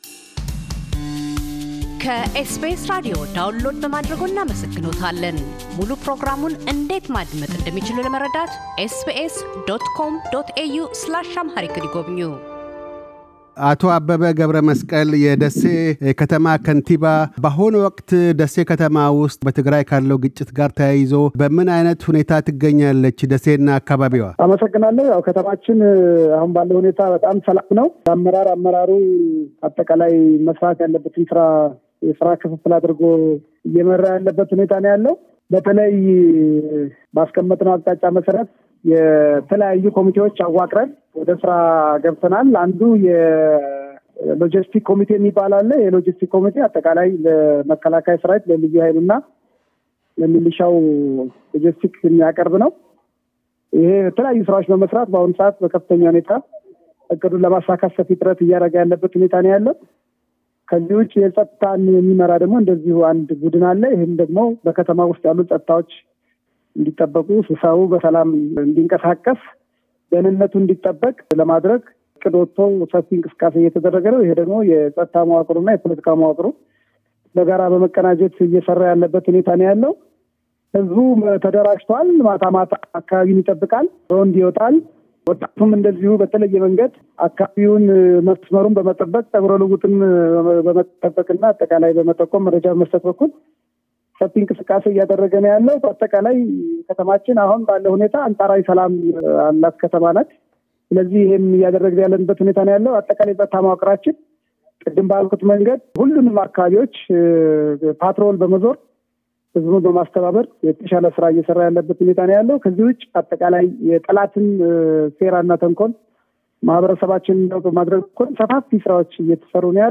የደሴ ከተማ ከንቲባ አበበ ገብረመስቀል፤ ደሴ ከተማ ውስጥ ለሚገኙ 55 ሺህ ስደተኞች የደሴ ሕዝባዊ ዕሴቶች በታየበት መልኩ ልገሳዎች እየተካሔዱ እንደሆነና ተጨማሪ እርዳታዎችንም እንደሚሹ ይናገራሉ።